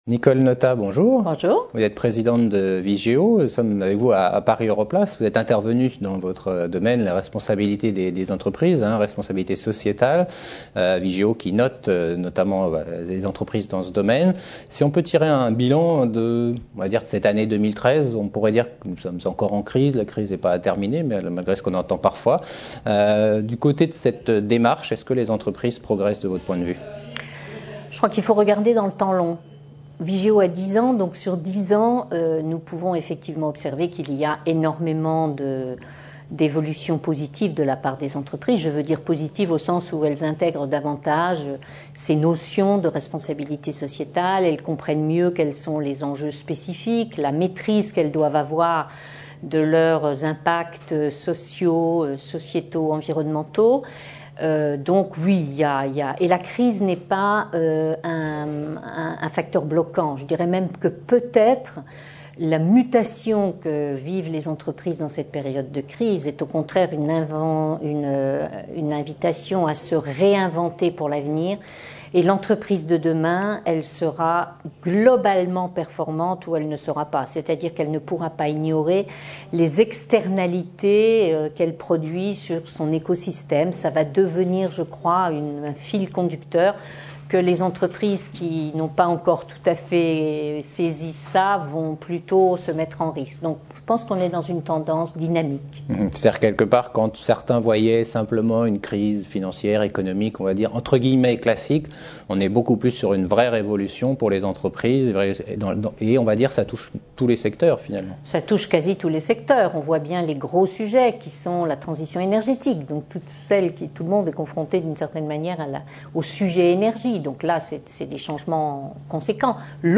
Interview Nicole Notat Présidente Vigeo.
Lors des Rencontres Paris Europlace 2013 qui avait pour thème cette année « Growth and Investment Opportunities in Europe », nous avons interviewé des personnalités qui participaient à ces échanges internationaux.